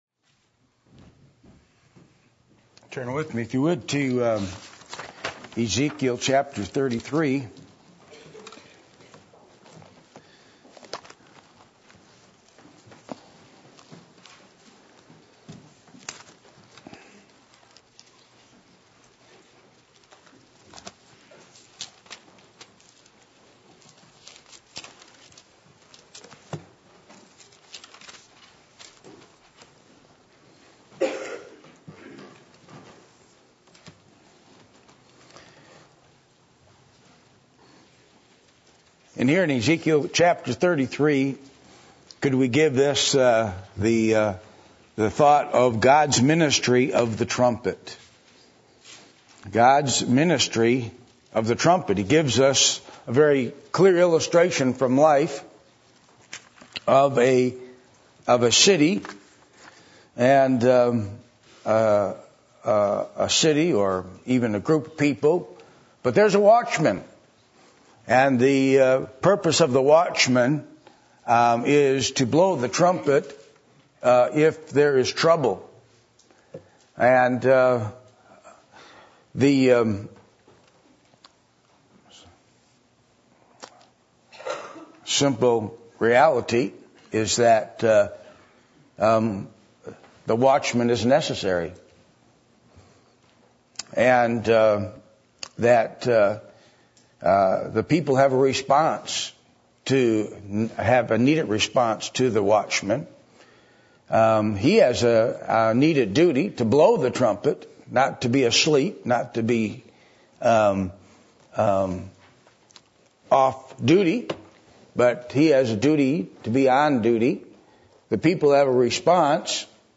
Passage: Ezekiel 33:11-16 Service Type: Sunday Morning